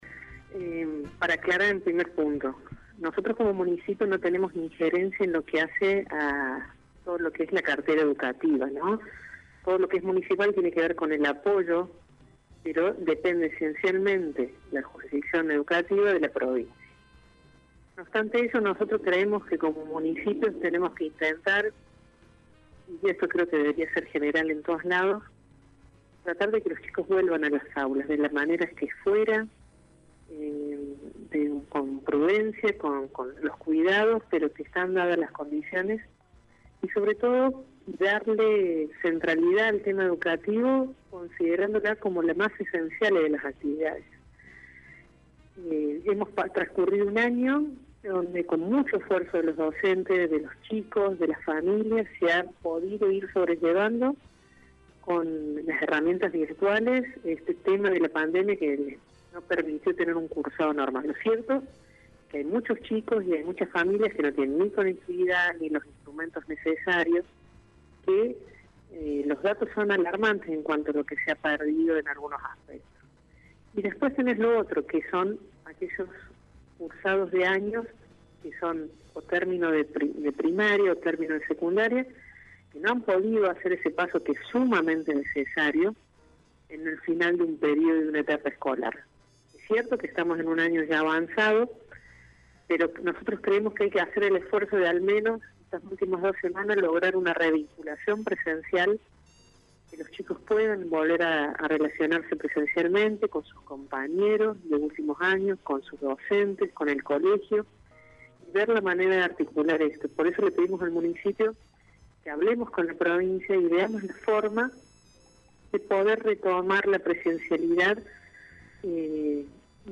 En diálogo con Radio Show, la concejala de Vamos Villa María, Karina Bruno explicó el pedido.